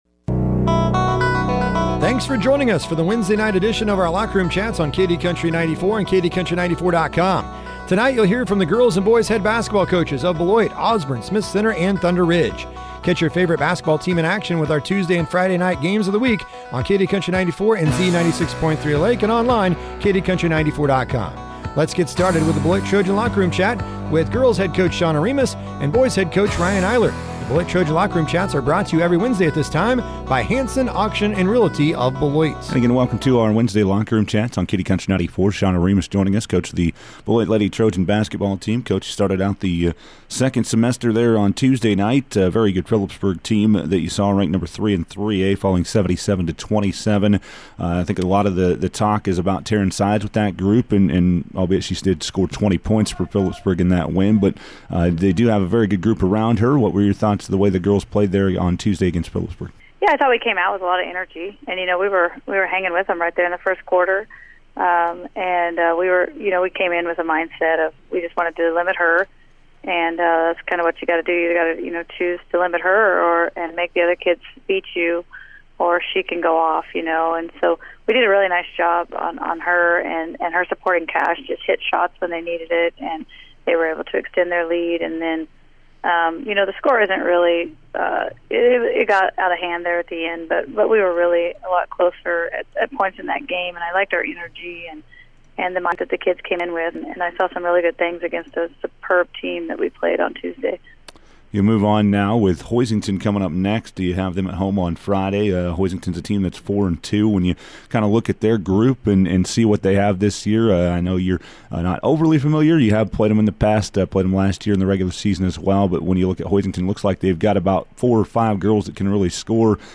are joined by the head basketball coaches